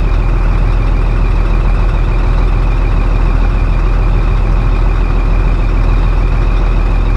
Truck engine.ogg